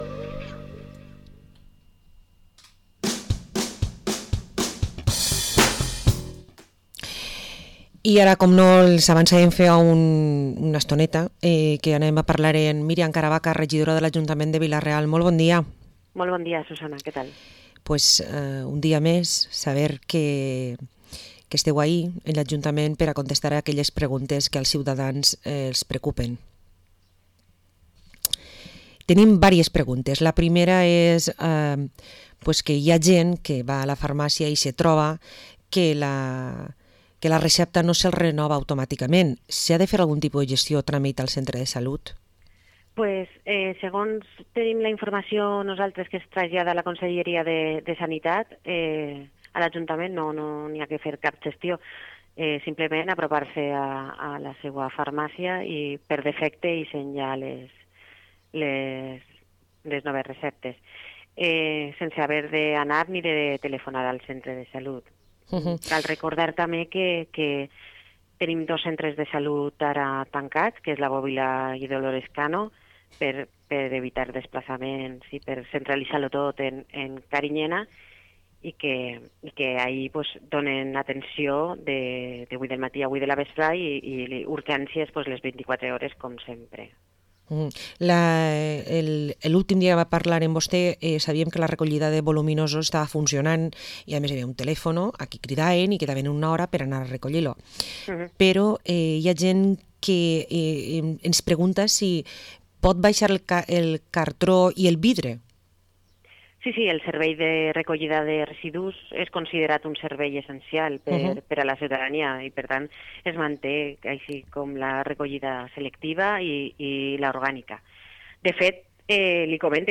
Entrevista a la regidora de participació ciutatana i proximitat de Vila-real, Miriam Caravaca